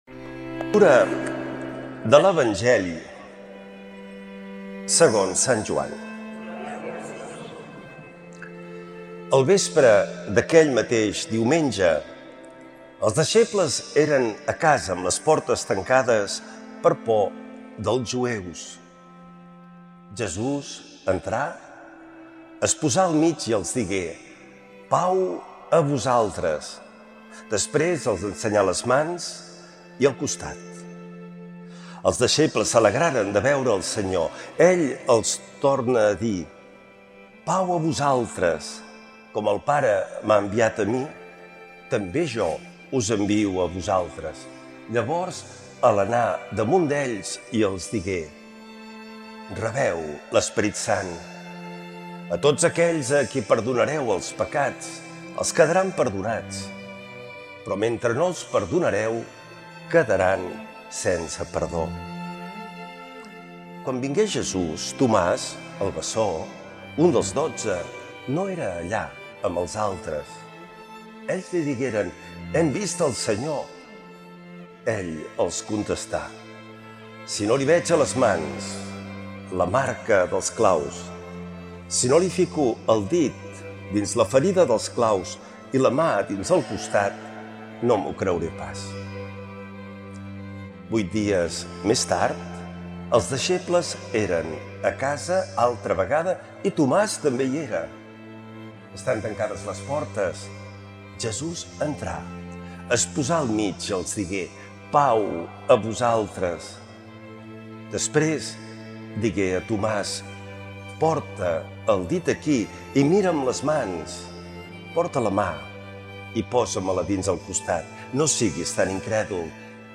Lectura de l’Evangeli segons Sant Joan.